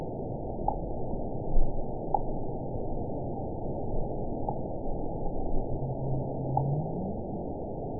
event 912508 date 03/28/22 time 06:03:57 GMT (3 years, 1 month ago) score 9.36 location TSS-AB03 detected by nrw target species NRW annotations +NRW Spectrogram: Frequency (kHz) vs. Time (s) audio not available .wav